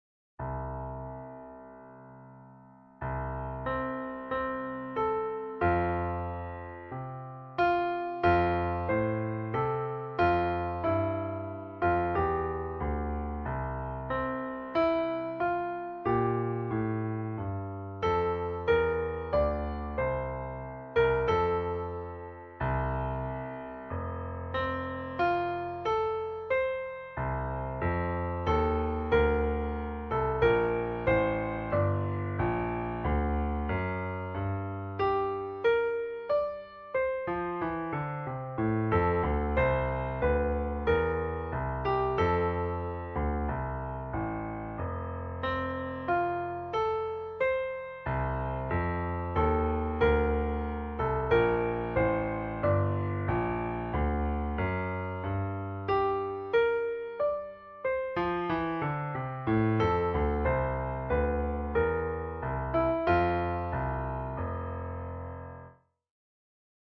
для фортепиано